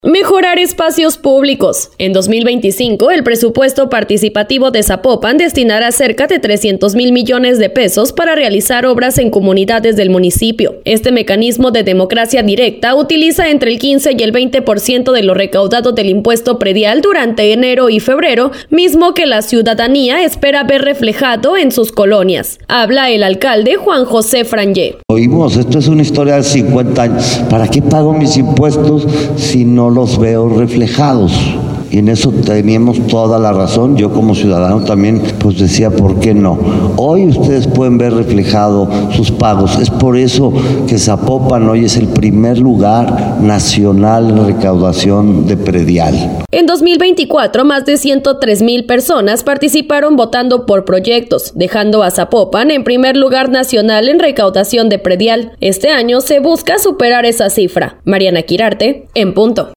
Señaló el alcalde, Juan José Frangie